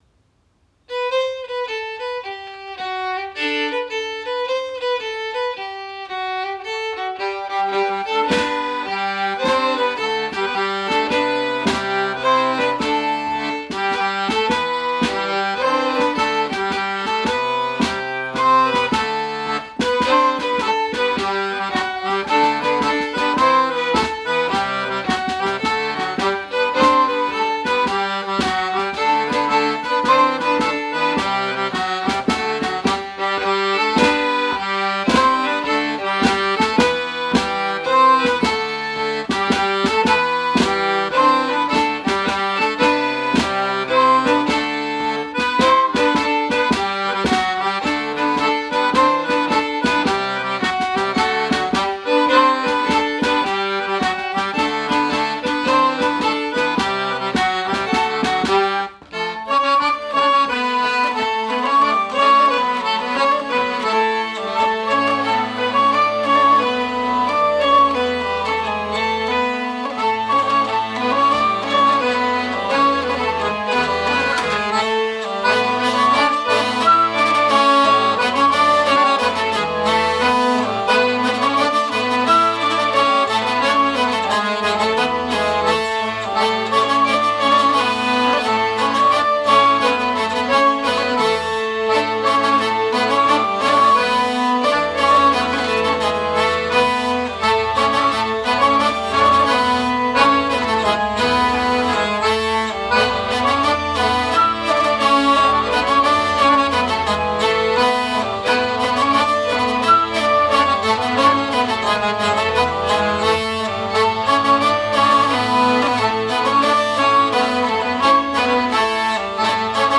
Répétition du 23/06/2012 - Spectacle de Reims - Musique
04 trégor Penthièvre.m4a